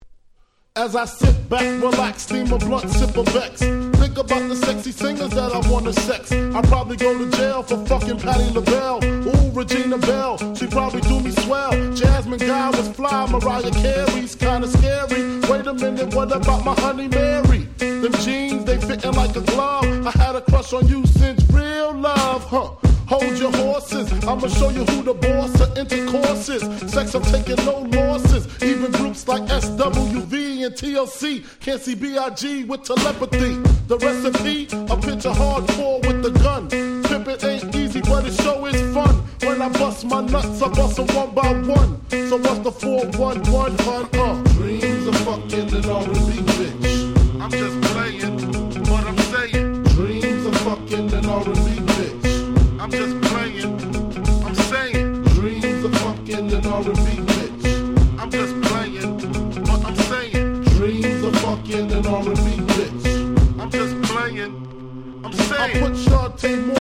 90's Hip Hop Super Classics !!